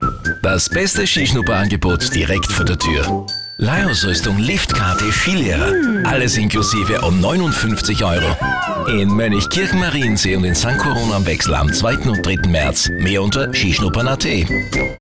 Radiospots